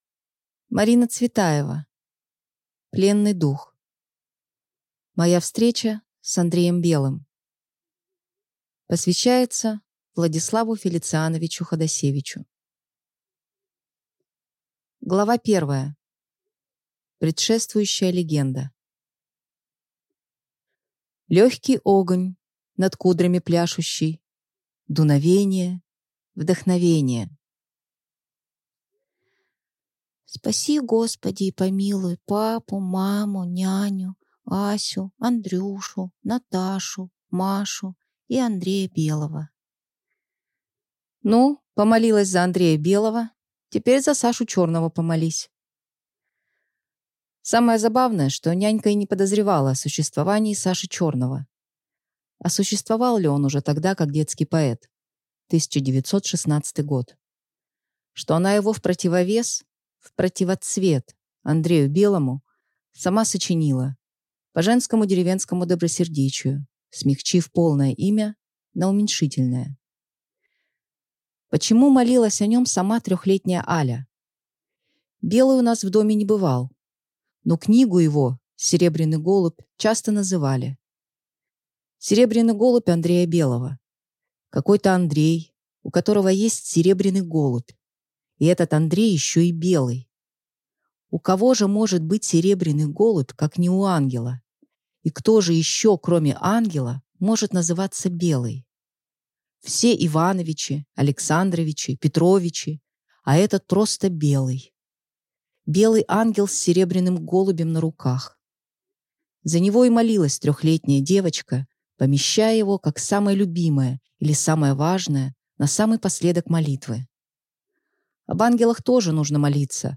Аудиокнига Пленный дух | Библиотека аудиокниг
Прослушать и бесплатно скачать фрагмент аудиокниги